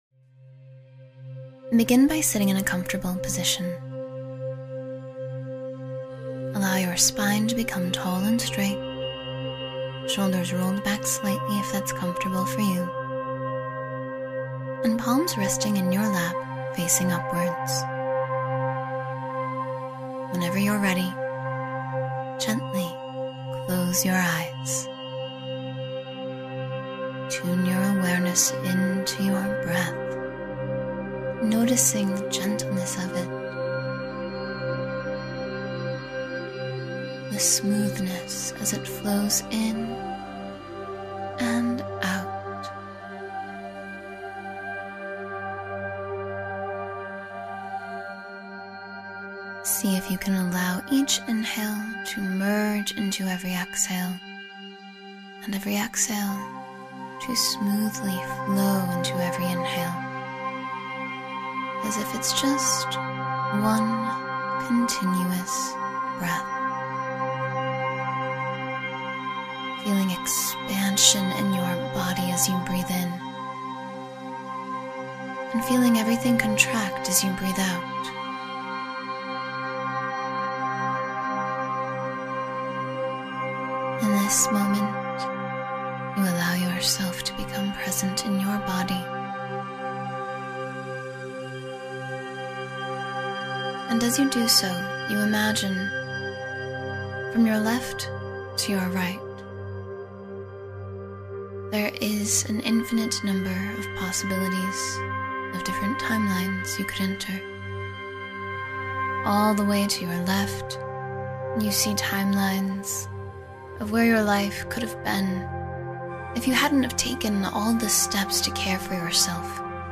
Become Your Best Self — A Guided Meditation for Daily Growth